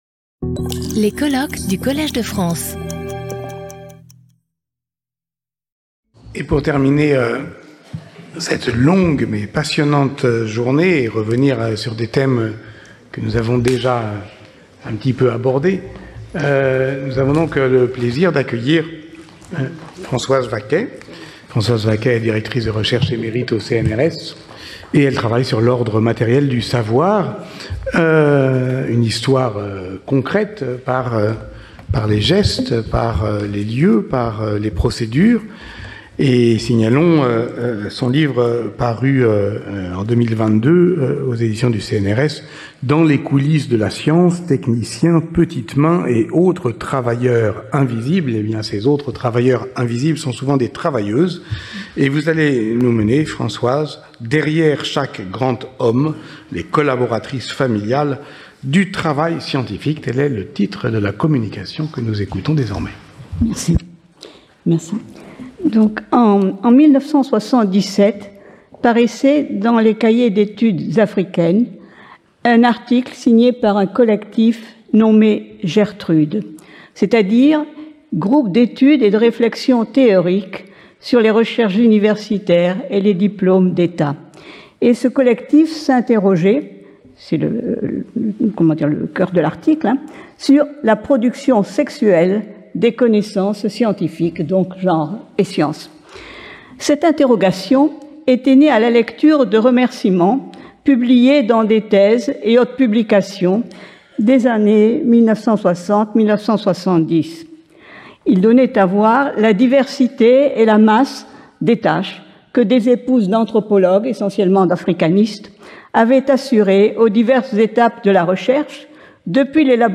Chaque communication de 30 minutes est suivie de 10 minutes de discussion.